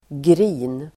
Uttal: [gri:n]